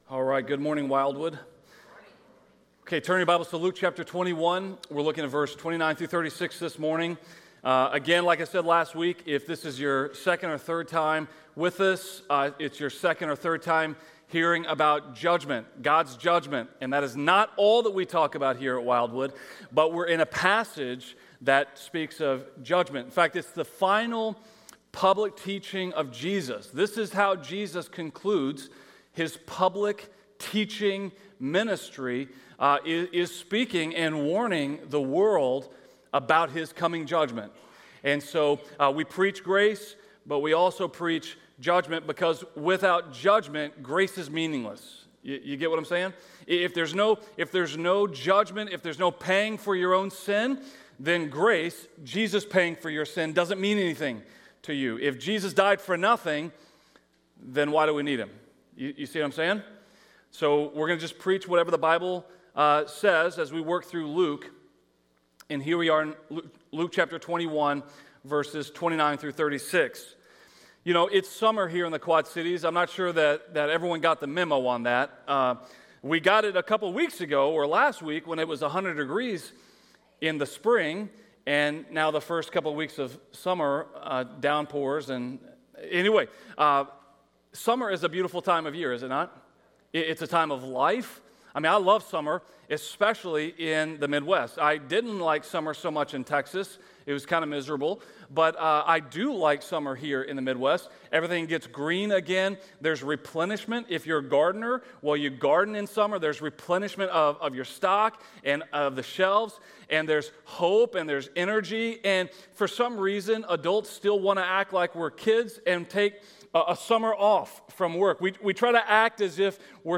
A message from the series "Relationships 101." Forgiveness might be the most difficult part of relationships!